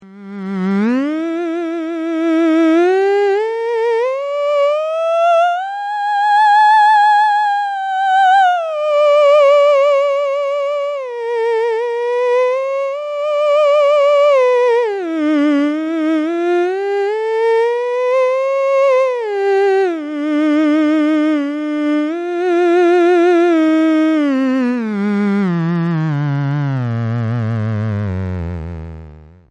My Tube/Valve.mp3  180k - All the buzz and sass of Clara Rockmore captured on a loose wire floating in the ether. This is the theremin to sound card direct, so no acoustic development.